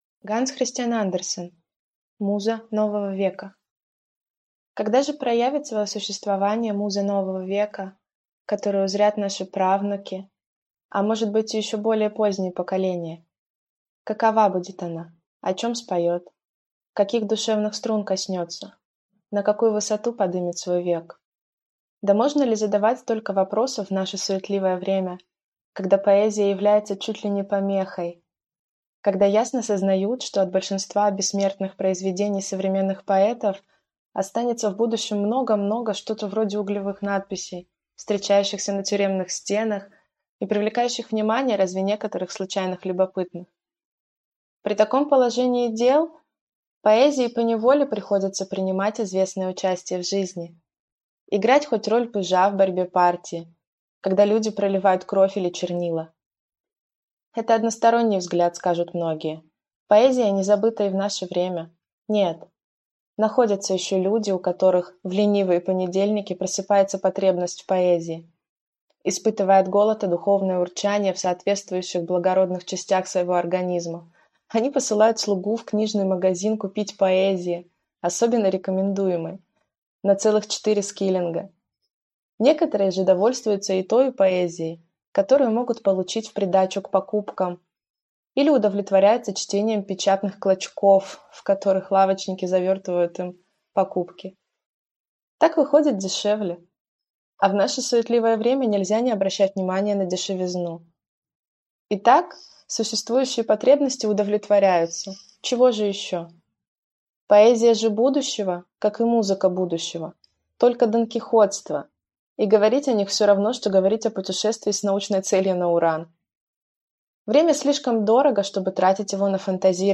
Аудиокнига Муза нового века | Библиотека аудиокниг